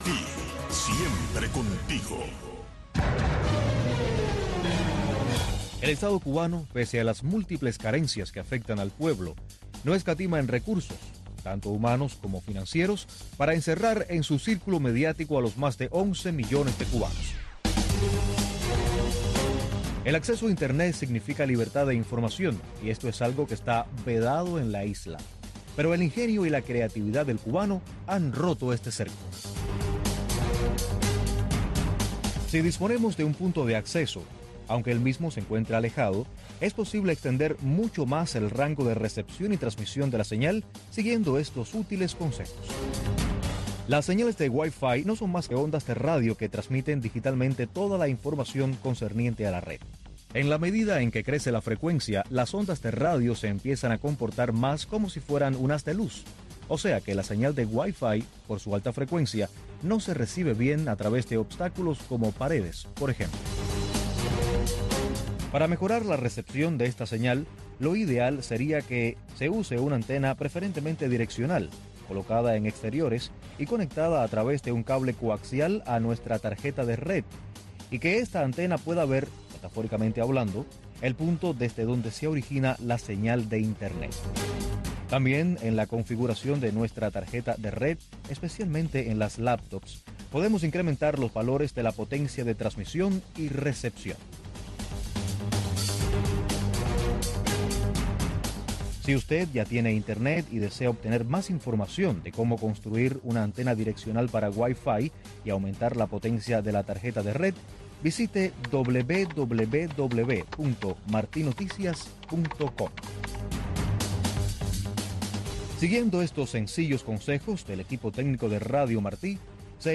PROGRAMACIÓN EN-VIVO DESDE LA ERMITA DE LA CARIDAD